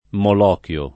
Molochio [ mol 0 k L o ]